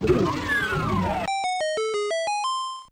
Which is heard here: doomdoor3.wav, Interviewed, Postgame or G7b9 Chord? doomdoor3.wav